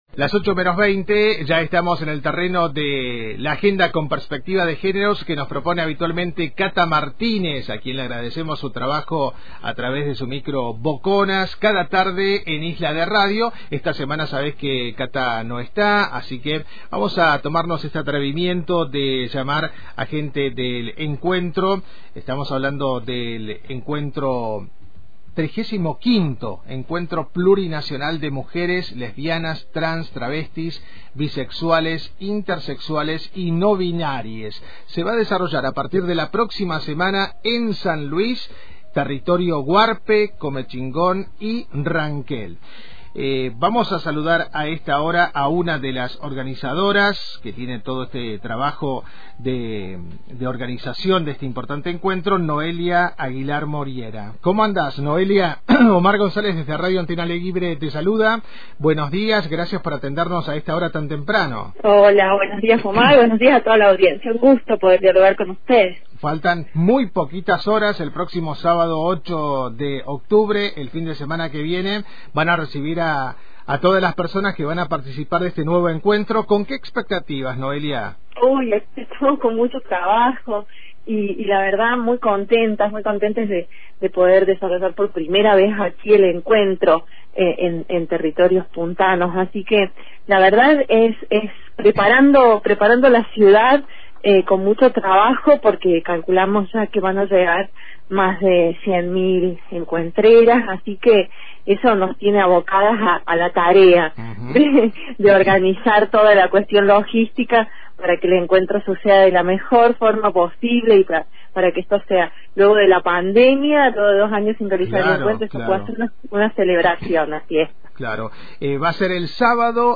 Serán más de 105 talleres los que se presenten en las jornadas, por lo que la participación activa es fundamental según el espíritu del Encuentro. Escuchá la entrevista completa